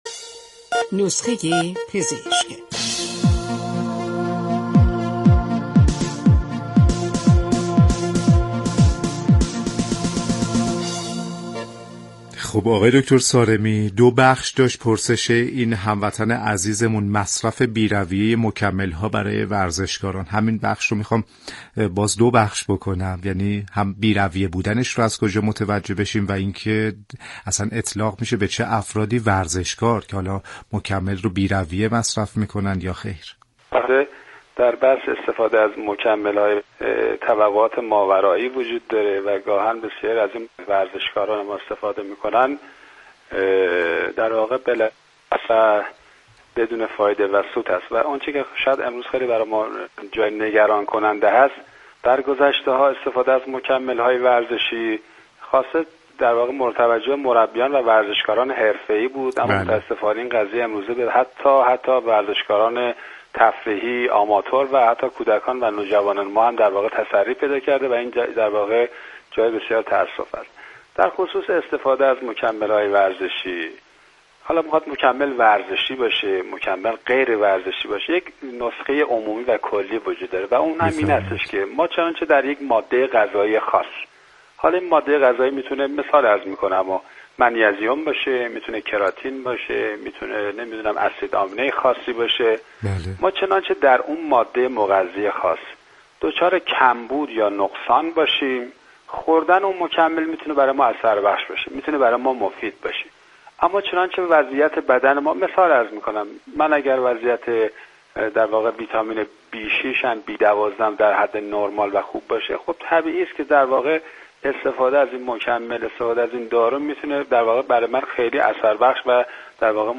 در گفت و گو با برنامه نسخه ورزشی رادیو ورزش